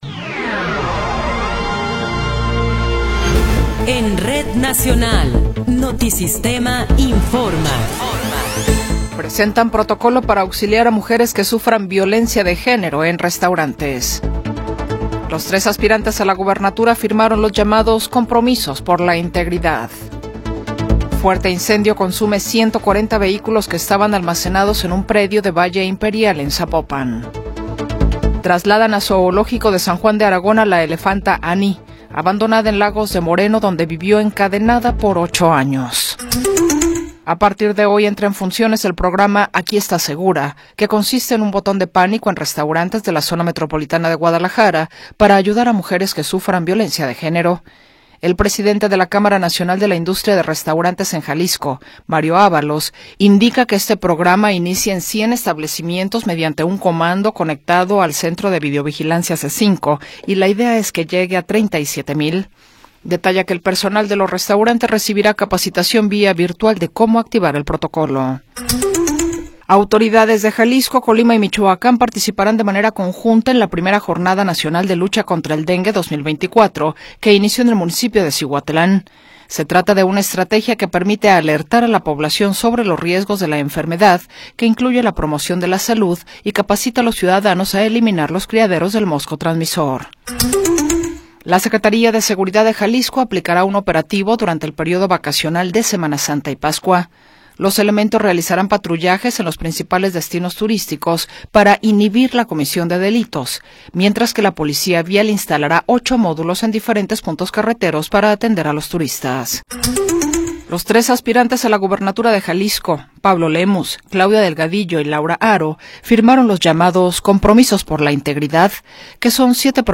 Noticiero 20 hrs. – 20 de Marzo de 2024
Resumen informativo Notisistema, la mejor y más completa información cada hora en la hora.